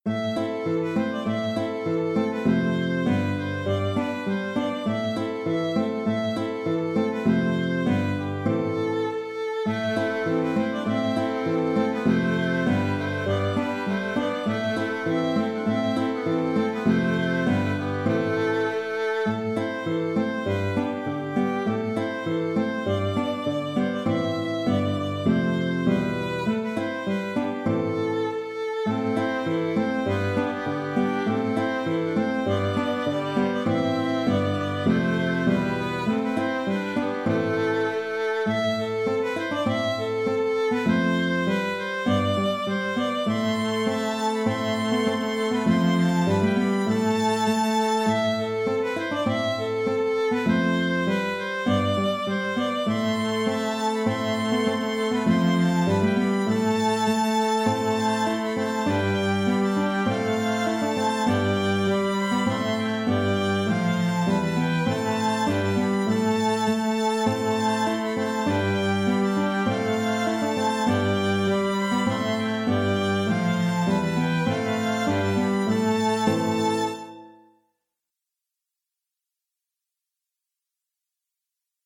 Danse de l'ours (Danse de l'ours) - Musique folk
Je propose deux contrechants.
Le second, sans doute moins, est plus destiné à terminer le morceau.